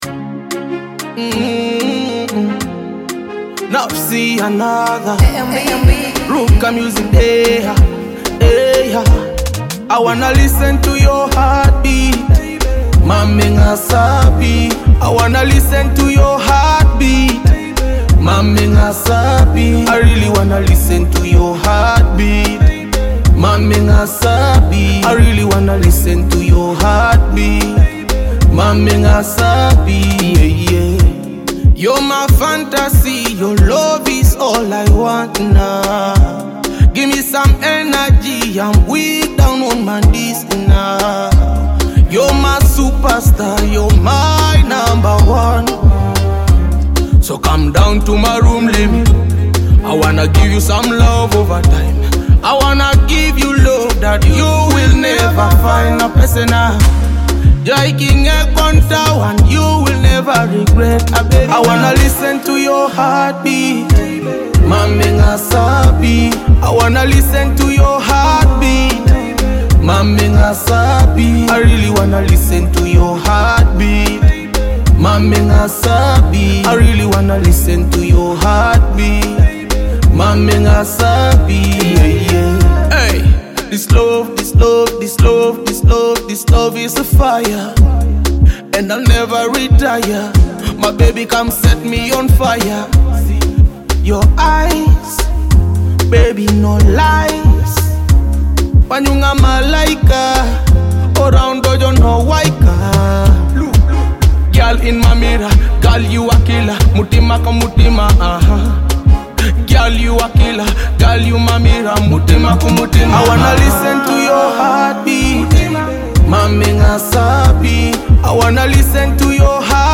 a captivating blend of rhythmic beats and powerful vocals.
Afrobeat